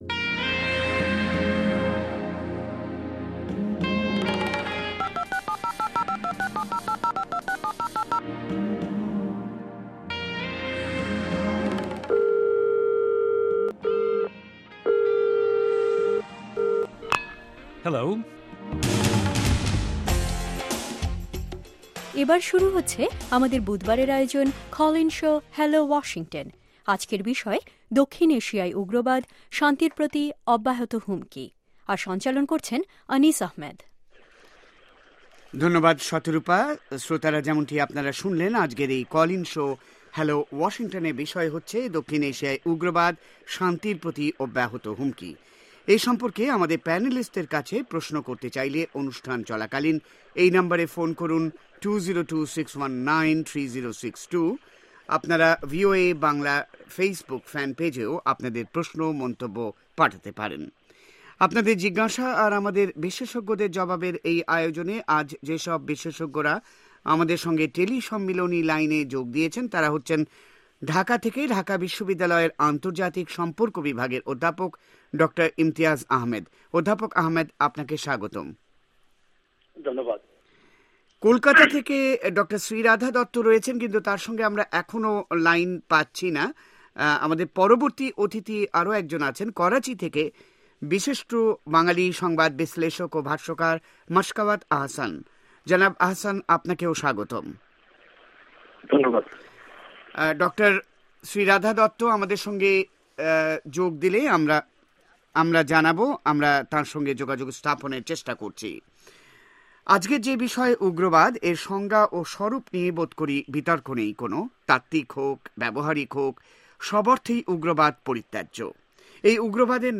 শুনুন কল ইন শো